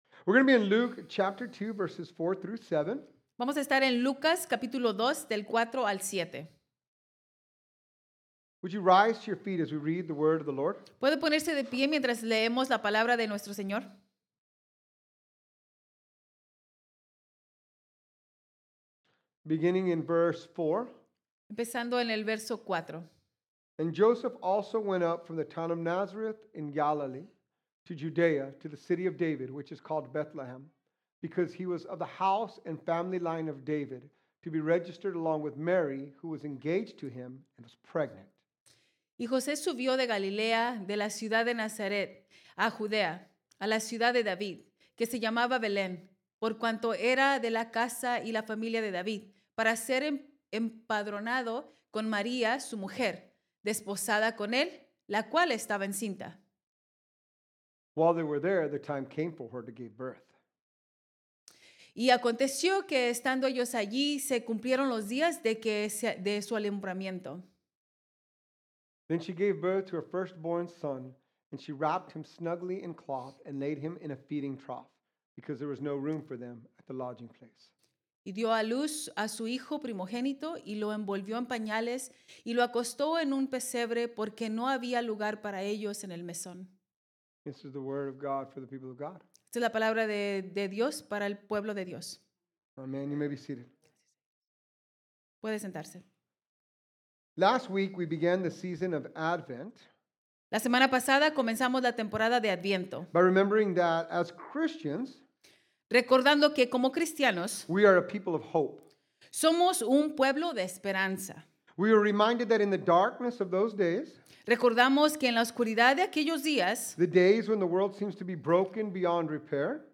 What a powerful and heartwarming Sunday we shared our special United worship Service!